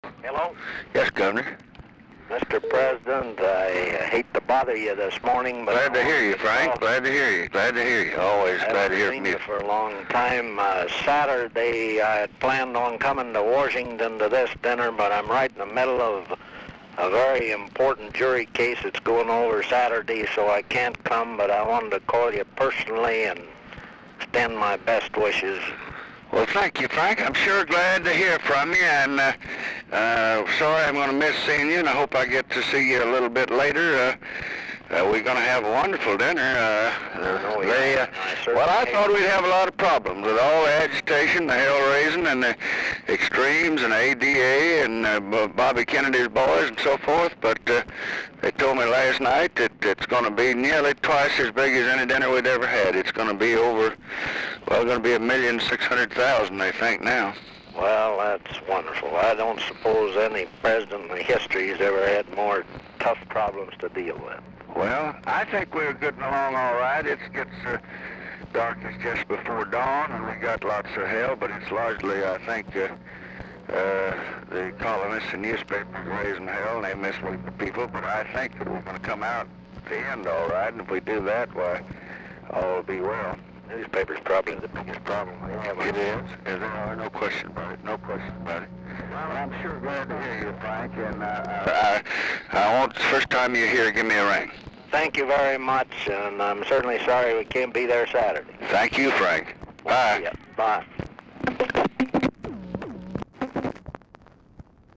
Title Telephone conversation # 12301, sound recording, LBJ and FRANK MORRISON, 10/5/1967, 9:35AM Archivist General Note "GOV.
Dictation belt